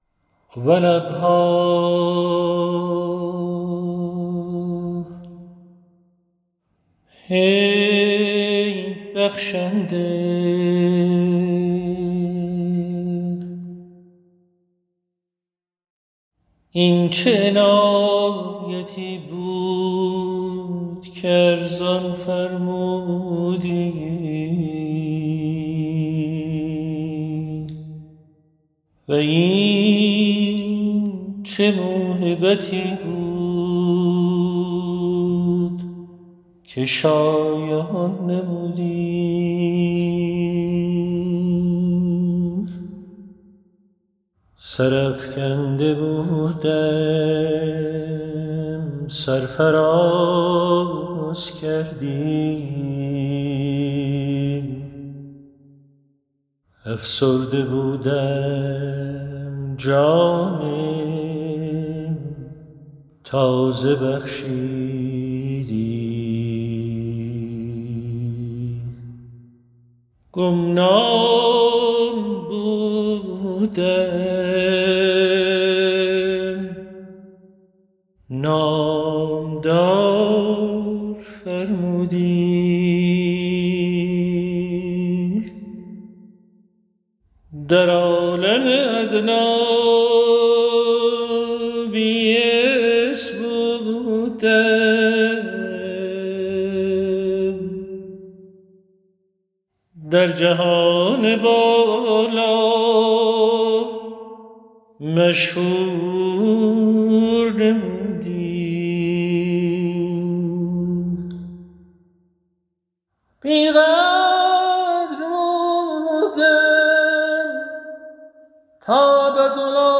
A beautiful Persian chant of the prayer that makes up the second half of this Tablet is available
chant_ab06174.m4a